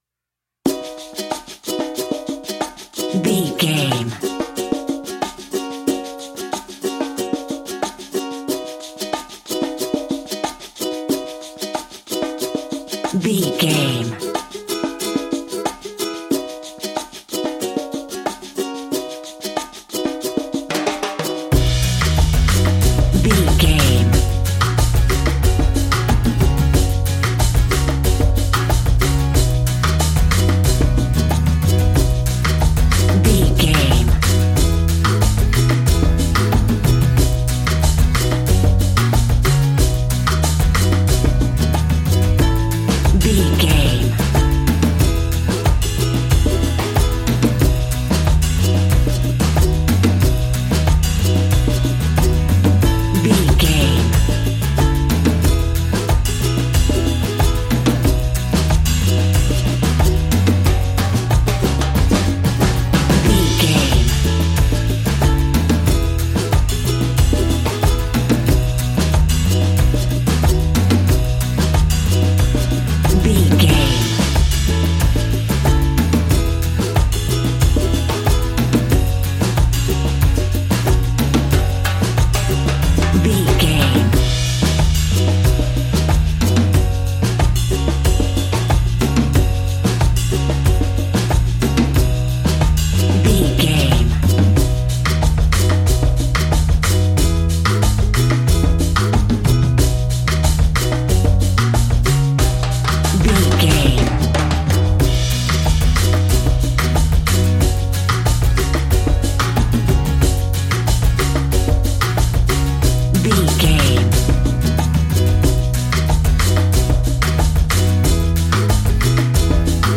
Ionian/Major
cheerful/happy
mellow
drums
electric guitar
percussion
horns
electric organ